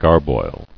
[gar·boil]